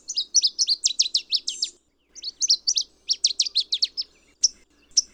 "Reinita Palmera"
"Palm Warbler"
Dendroica palmarum
reinita-palmera.wav